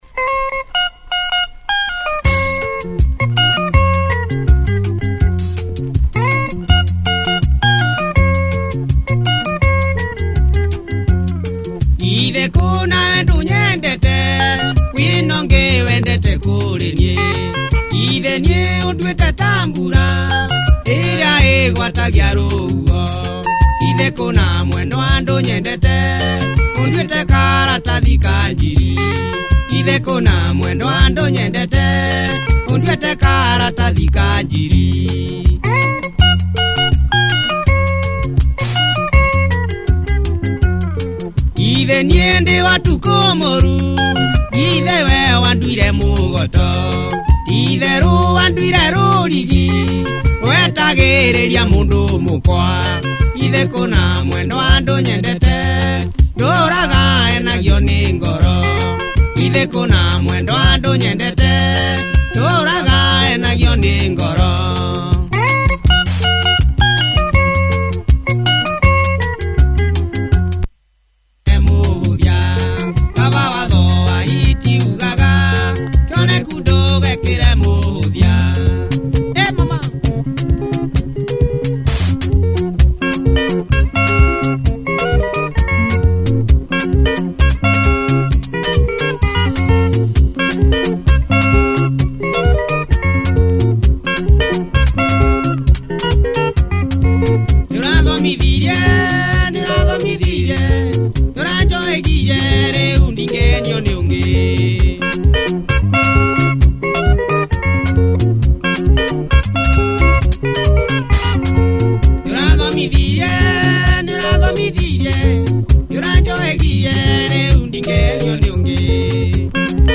Slowburn Benga
steps it up mid-way!